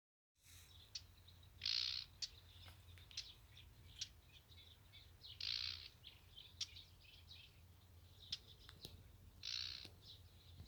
болотная камышевка, Acrocephalus palustris
СтатусВзволнованное поведение или крики